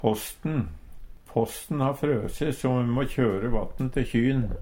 posten - Numedalsmål (en-US)